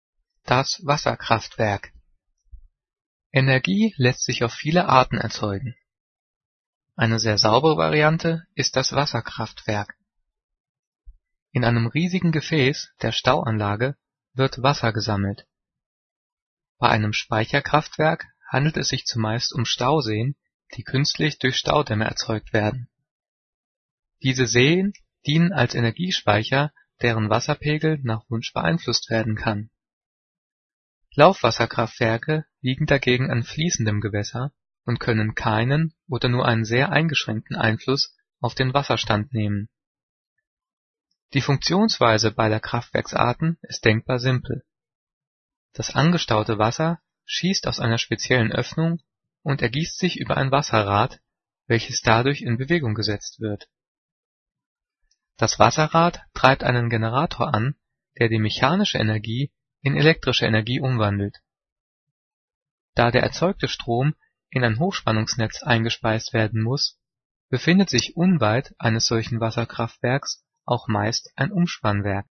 Diktat: "Das Wasserkraftwerk" - 7./8. Klasse - s-Laute
Gelesen:
gelesen-das-wasserkraftwerk.mp3